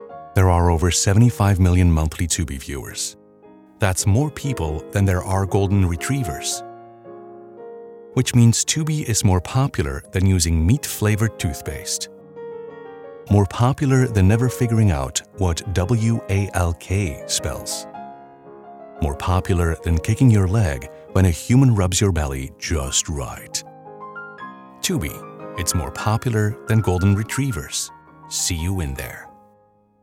Middle Aged
His sound? Modern, smooth, and unmistakably human.